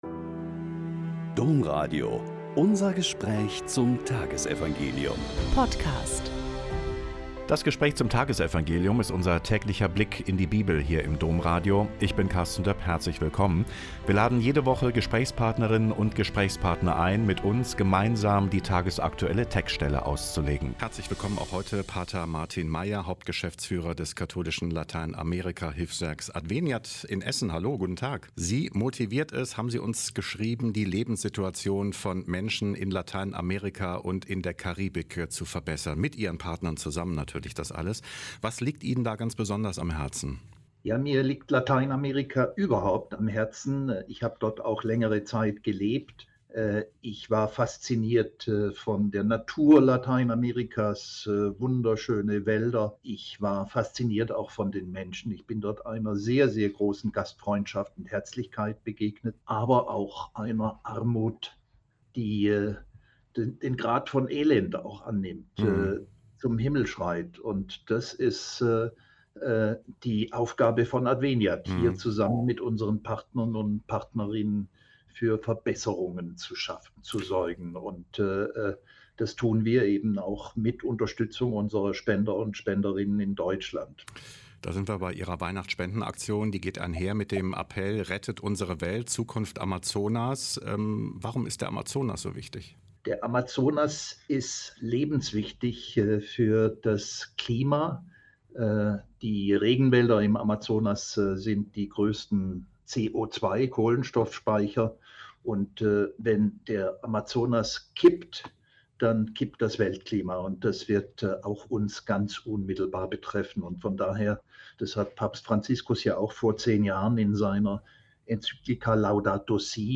Mt 11,28-30 - Gespräch